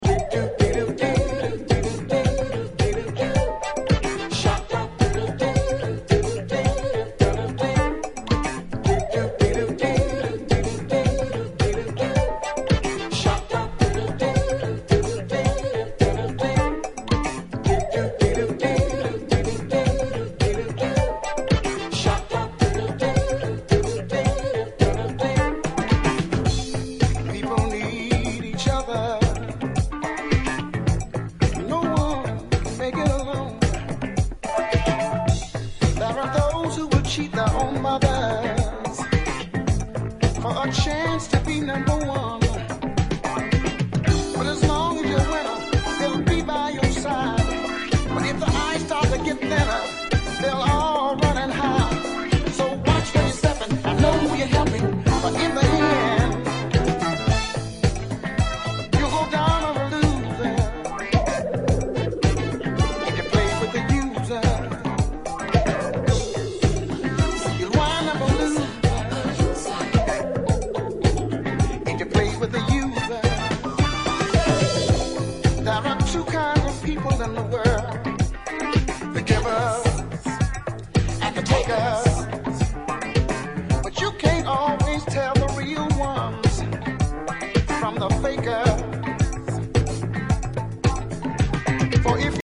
drop two funk fuelled bombs that are masterpieces!
Disco House Funk